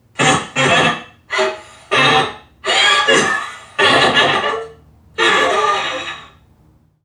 NPC_Creatures_Vocalisations_Robothead [46].wav